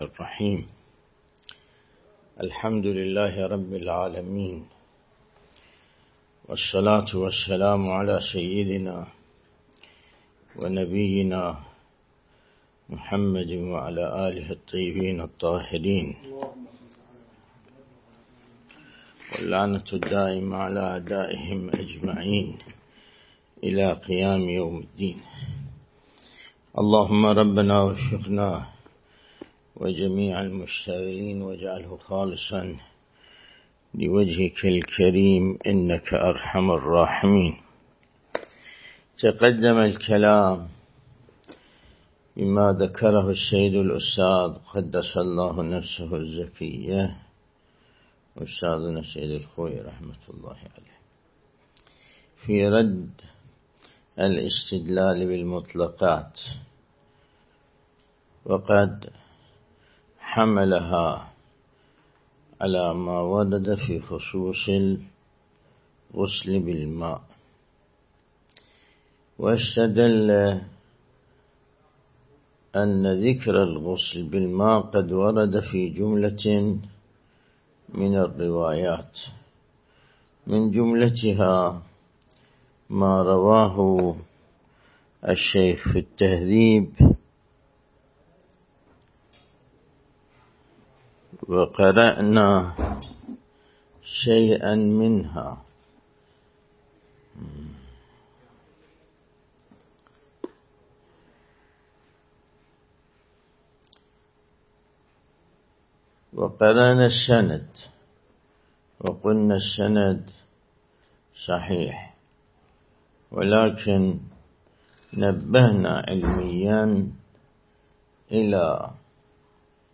الدرس الاستدلالي شرح بحث الطهارة من كتاب العروة الوثقى